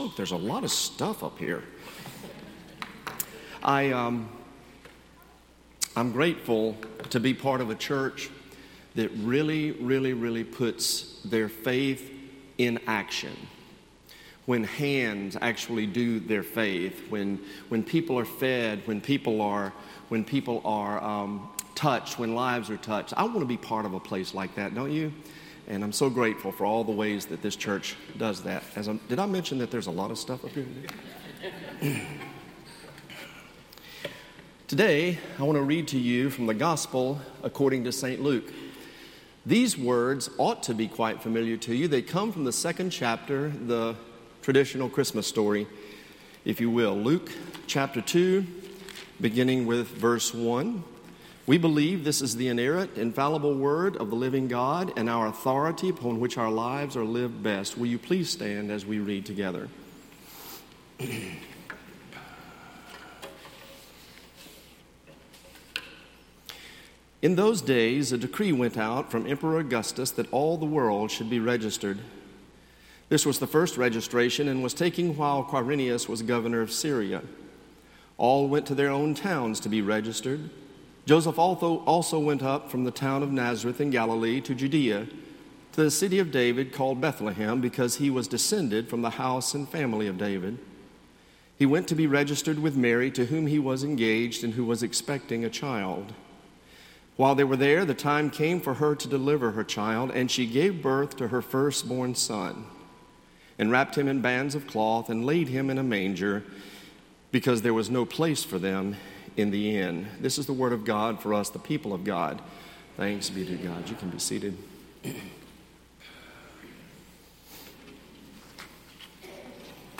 Sermon Archives - Cokesbury Church